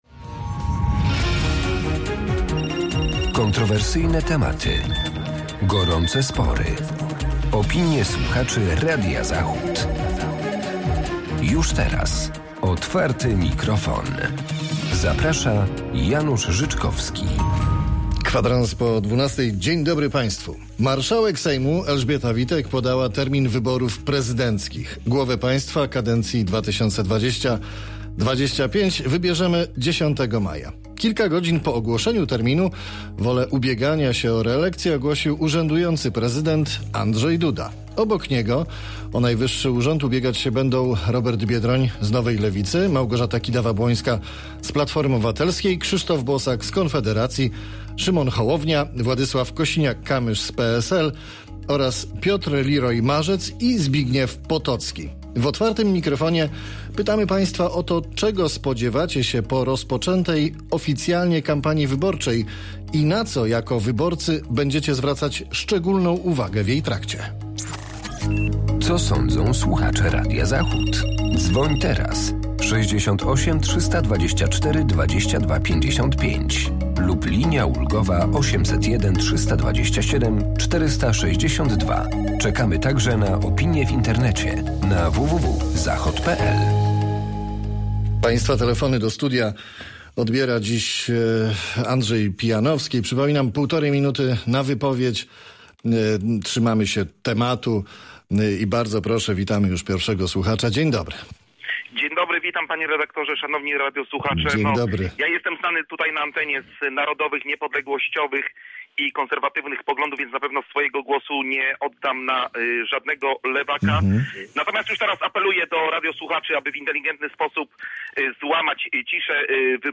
W Otwartym mikrofonie pytamy Państwa o to czego spodziewacie się po rozpoczętej oficjalnie kampanii wyborczej i na co jako wyborcy będziecie zwracać szczególną uwagę w jej trakcie?